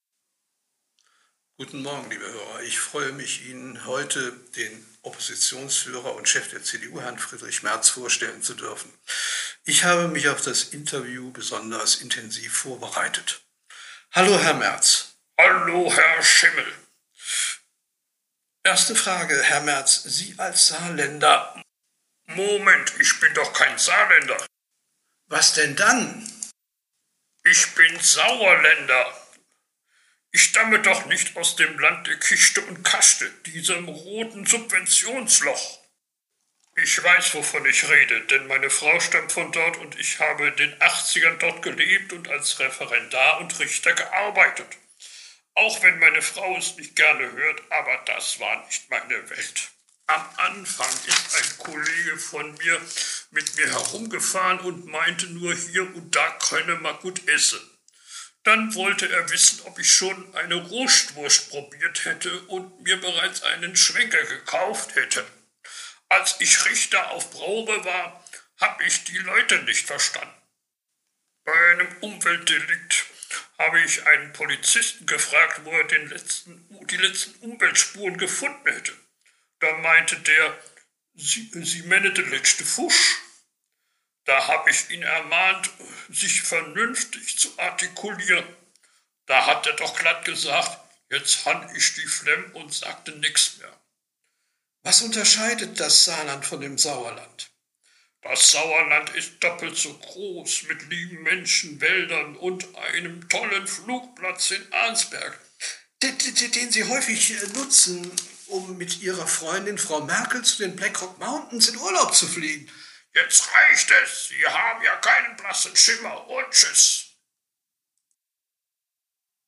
Merz Interview 1